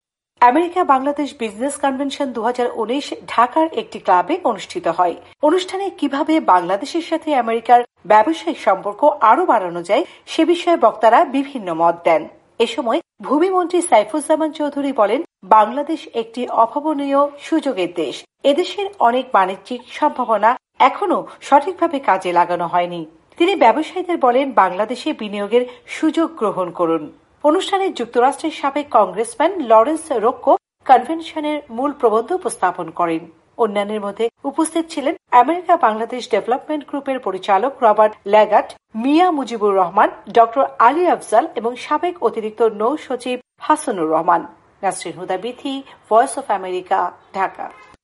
আমেরিকা-বাংলাদেশ বিজনেস কনভেনশন ২০১৯ ঢাকার একটি ক্লাবে অনুষ্ঠিত হয়। অনুষ্ঠানে কিভাবে বাংলাদেশের সাথে আমেরিকার ব্যবসায়িক সম্পর্ক আরো বাড়ানো যায় সে বিষয়ে বক্তারা বিভিন্ন মত দেন।